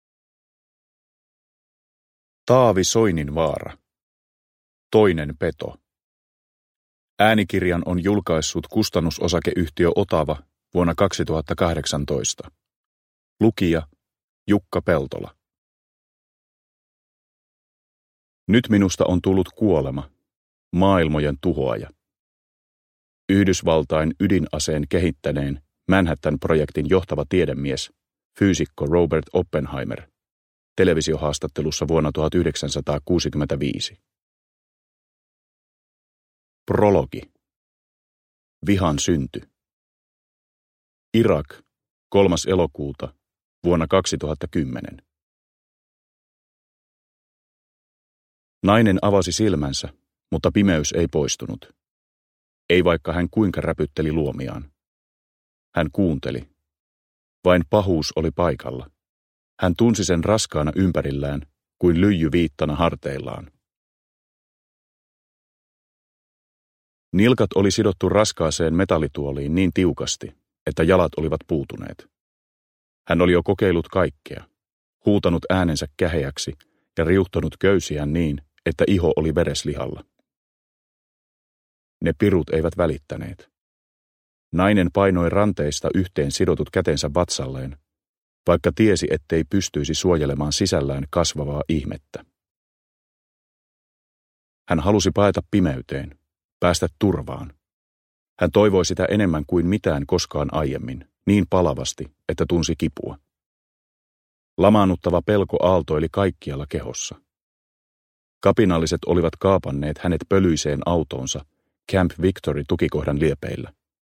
Toinen peto – Ljudbok – Laddas ner
Uppläsare: Jukka Peltola